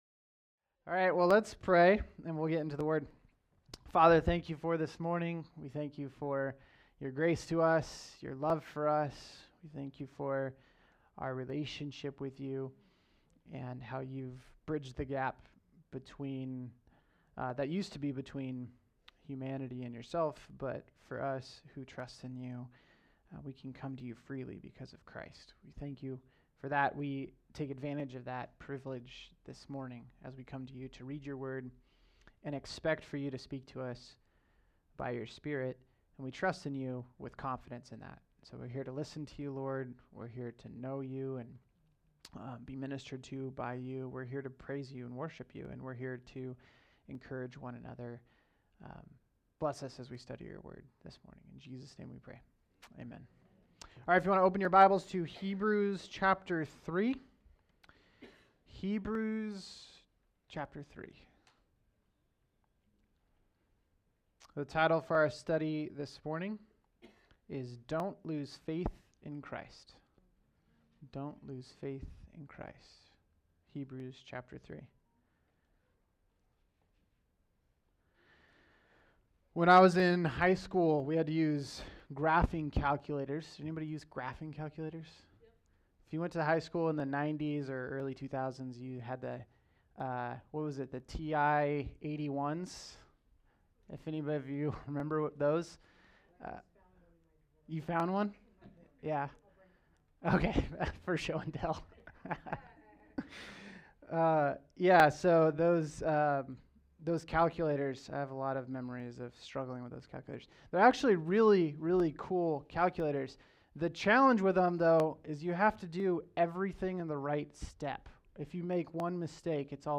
All Sermons
the Greatest Topic: Sunday Morning 2025 Book